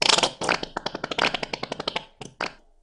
チューブから出す１